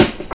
Snare (26).wav